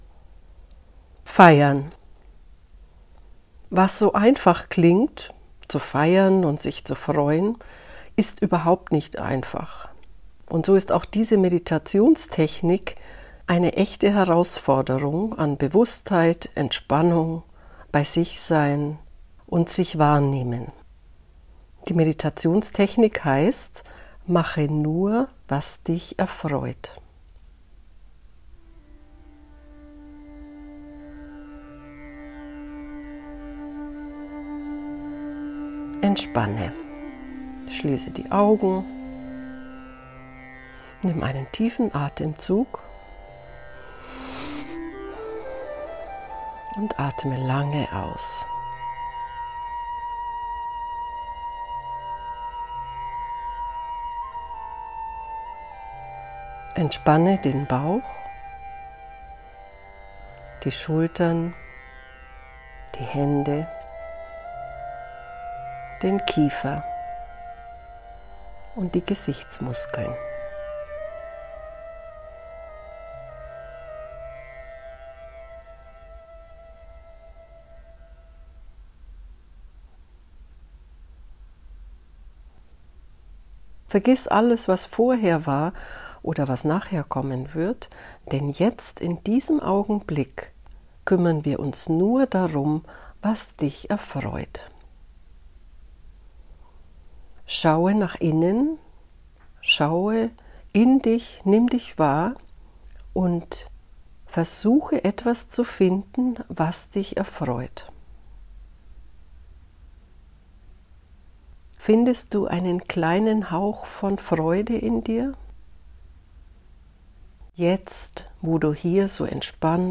Wenn es dir schwerfällt, dir die Zeit zu nehmen, dann mögen diese kurzen, intensiven Audio-Anleitungen genau das Richtige für dich sein.
Hörprobe: Audioanleitung zum Feiern
06-feiern-meditation.mp3